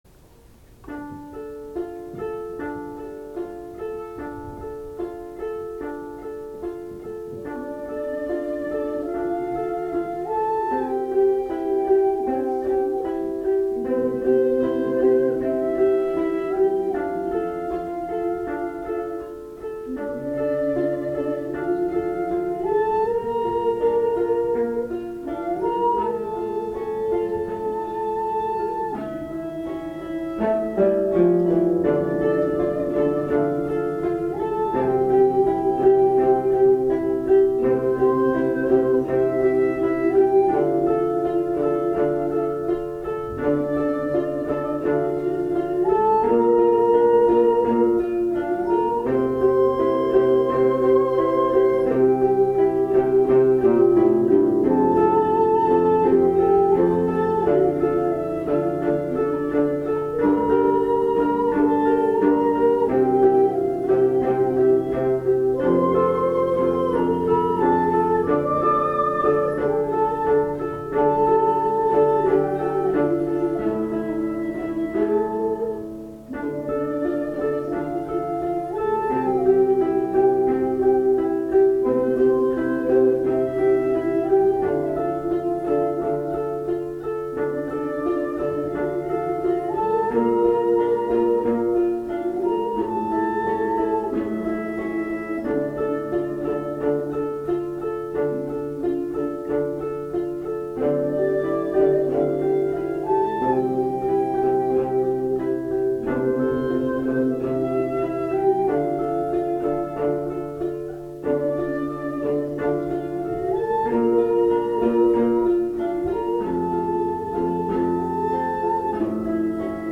２０２５年１１月３日、泉佐野市有形文化財新川家におきまして秋の演奏会が実施されました。
会場の皆様にはずっと聞き続けるという受け身の音楽会でしたがせめて最後は声をだしていただこうということで、
歌詞を知らなくても「ル～～～」と歌える曲です。
十七絃がベースをとり、お琴が分散和音で始まり尺八がメロディーをなぞります。
なんと尺八の音色が「声」に聞こえ、歌の「ル～～」が楽器のような音色なのです。